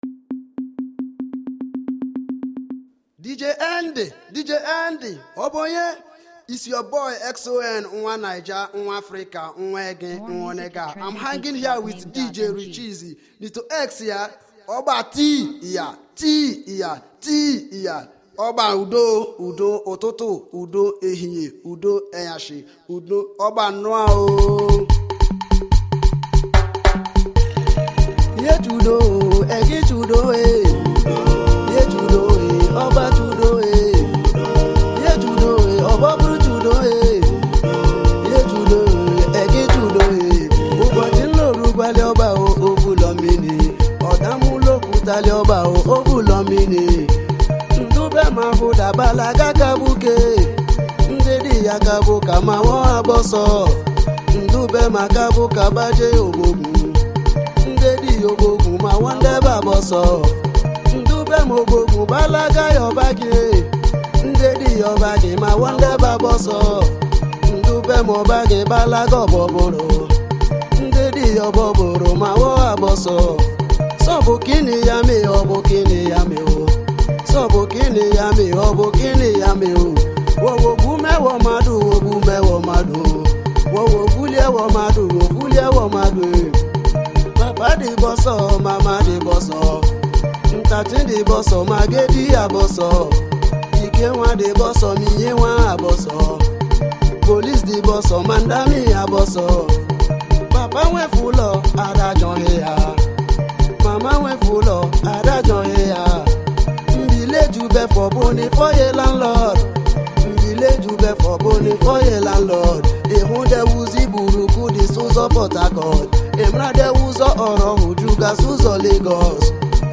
Cultural Songs